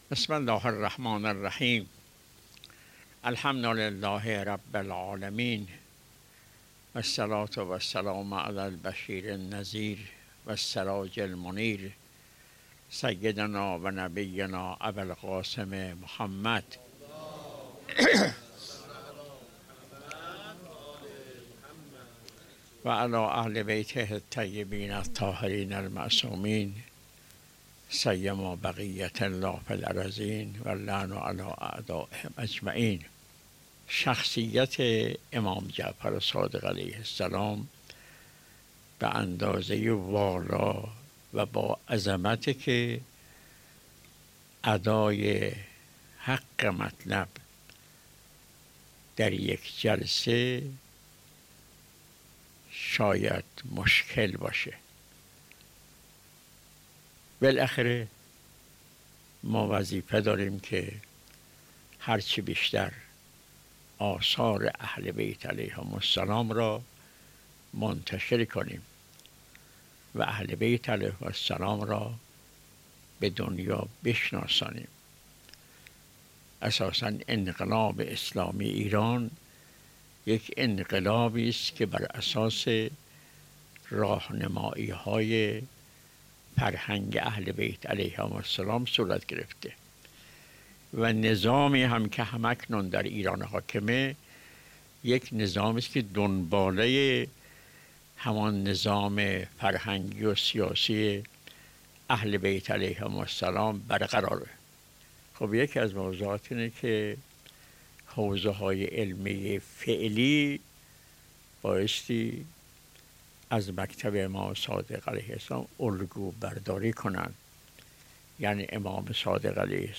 به گزارش خبرنگار سرویس سیاسی خبرگزاری رسا، حضرت آیت الله نوری همدانی در مراسمی که به مناسبت شهادت امام جعفر صادق علیه السلام در دفتر این مرجع تقلید برگزار شد، با بیان اینکه باید شخصیت و سیره ششمین امام معصوم بیشتر در جامعه تبیین و تفسیر کنیم، ابراز داشت: ما وظیفه داریم که هرچه بیشتر، آثار اهل بیت (علیهم السلام) را منتشر کنیم و اهل بیت (علیهم السلام) را به دنیا بشناسانیم.